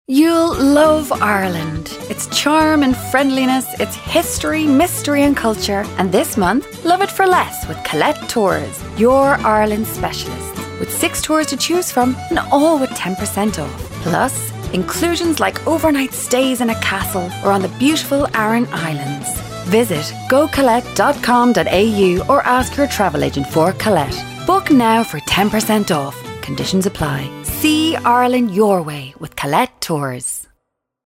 Female
30s/40s, 40s/50s
Irish Dublin Neutral, Irish Neutral